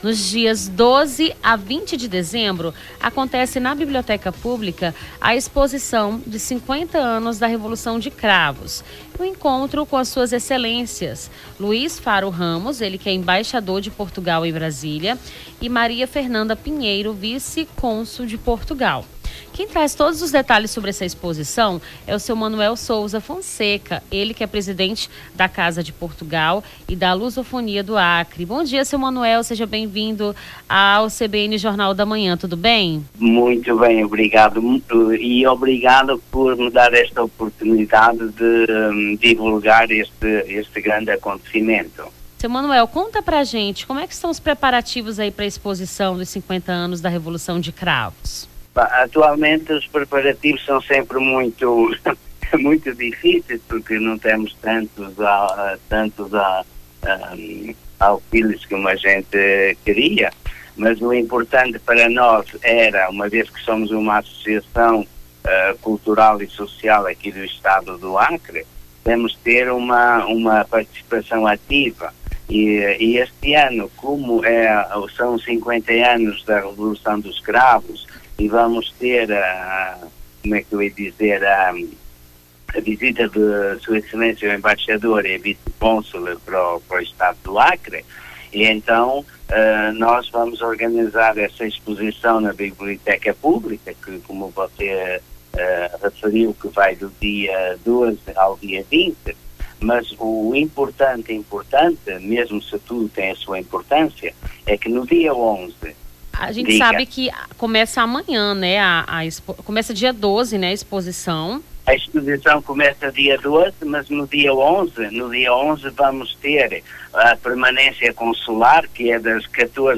Nome do Artista - CENSURA - ENTREVISTA EXPOSIÇÃO 50 ANOS REVOLUÇÃO (10-12-24).mp3